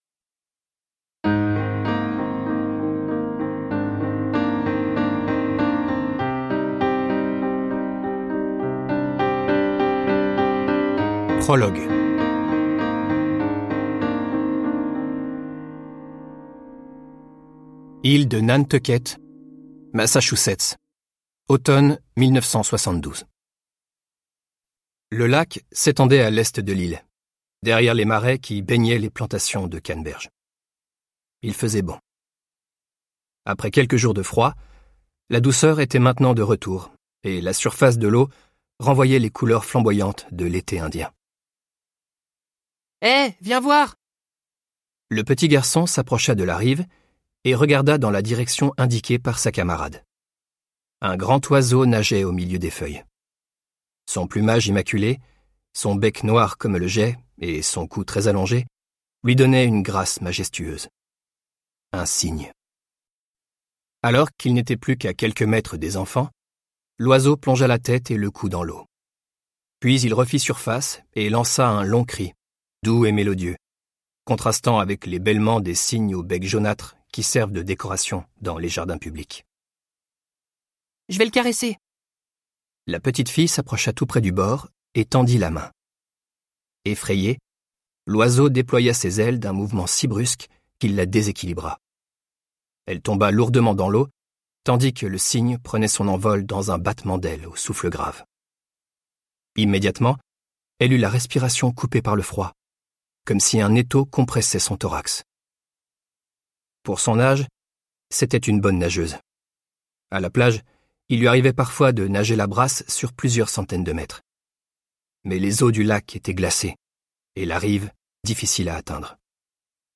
Extrait gratuit - Et après... de Guillaume Musso